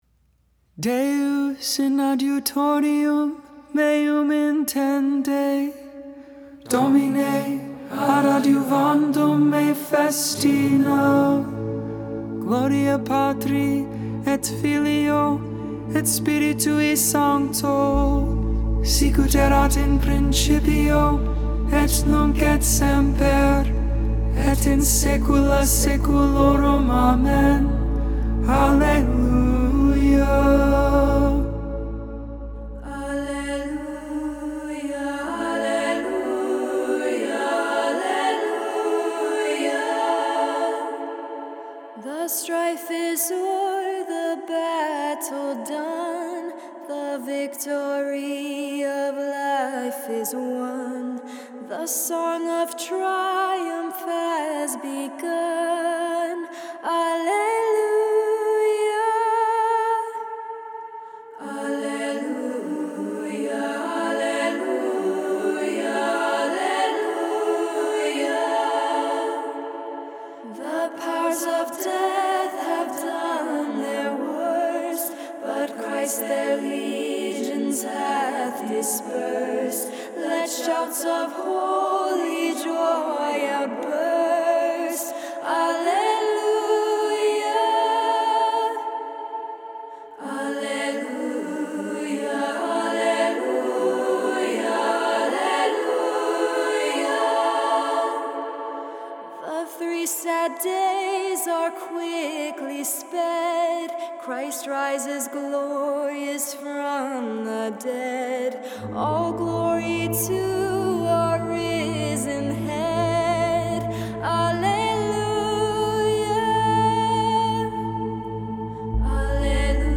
Magnificat: Luke 1v46-55 (English, tone 8) Intercessions: Lord Jesus, you live for ever; hear our prayer.